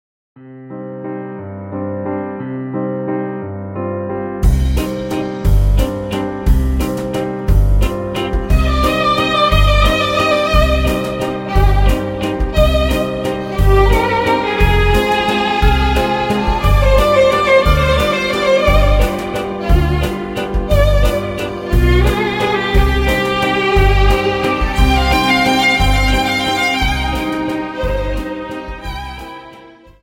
Dance: Viennese Waltz 59